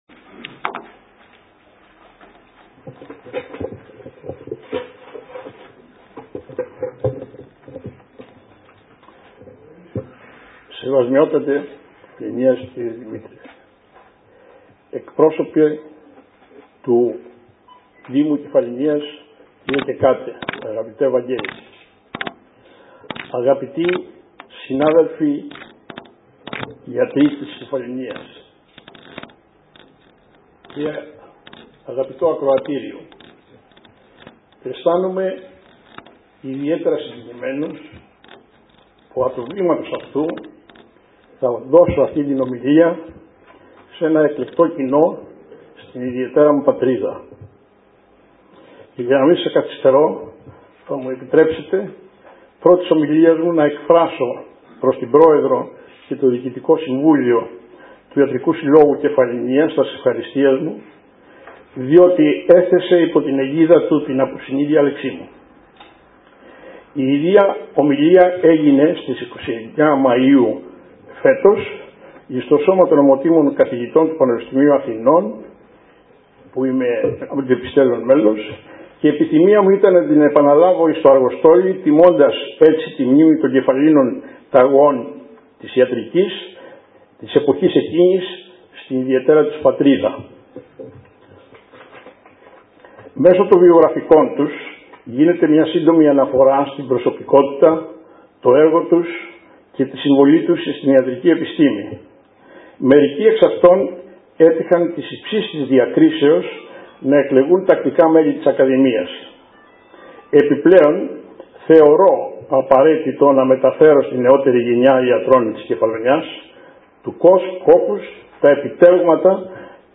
Παραβρέθηκαν ο Σεβασμιότατος Μητροπολίτης κ. Δημήτριος και ο αντιδήμαρχος κ. Βαγ. Κεκάτος όπου και χαιρέτησαν την εκδήλωση.